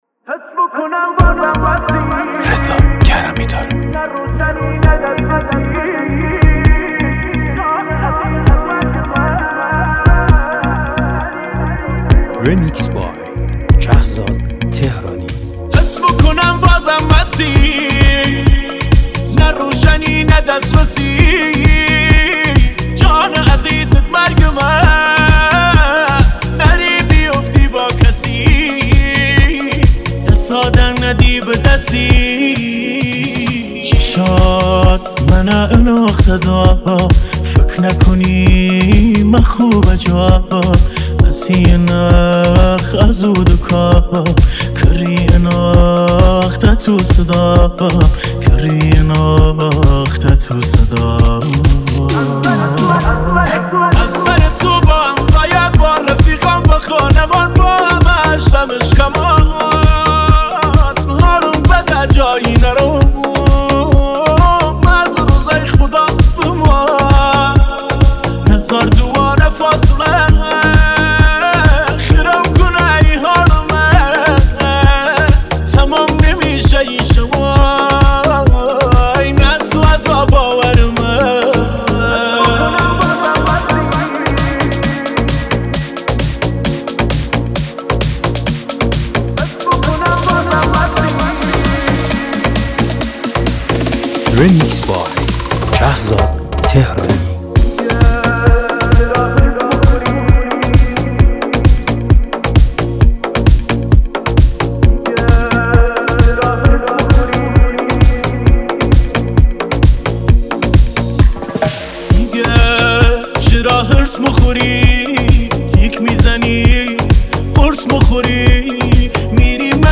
دانلود ریمیکس جدید